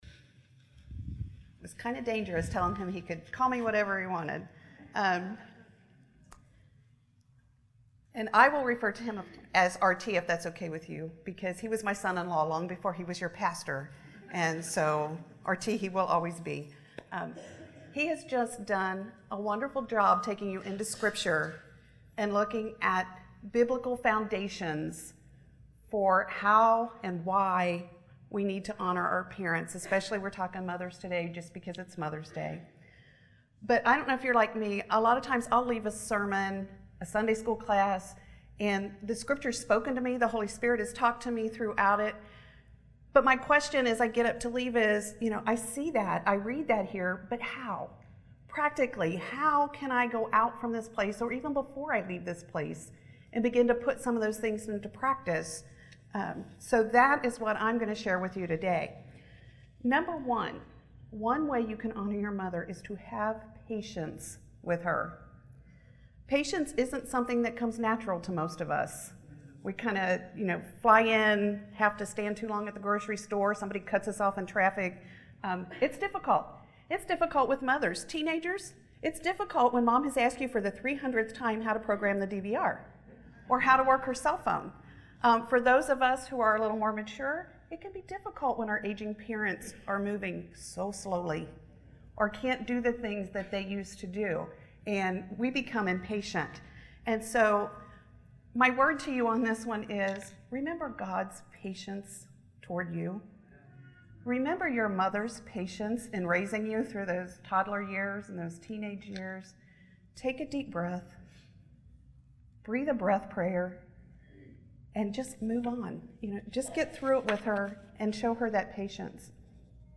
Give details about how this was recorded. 13 Biblical and Practical Ways to Show Honor to Your Mother Mother’s Day Service at Villa Baptist Church in Indianapolis, IN Mother's Day May 16